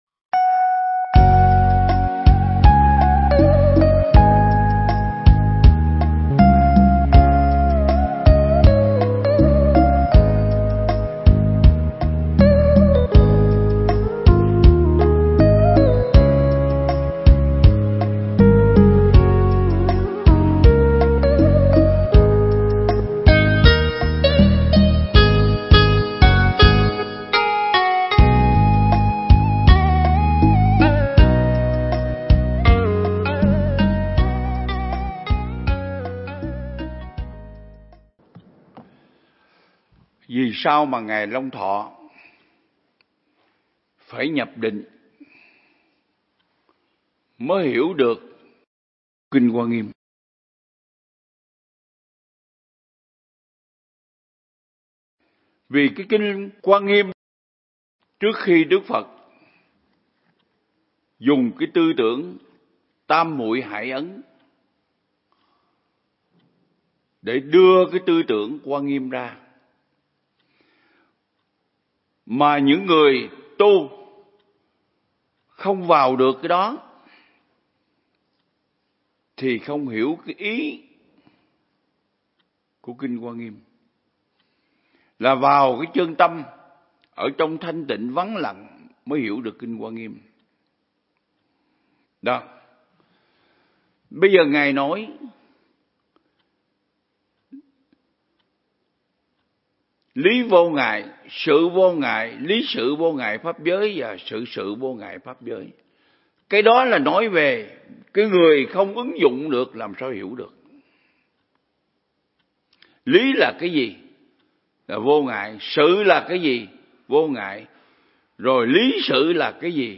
Mp3 Pháp Thoại Ứng Dụng Triết Lý Hoa Nghiêm Phần 7
giảng tại Viện Nghiên Cứu Và Ứng Dụng Buddha Yoga Việt Nam (TP Đà Lạt)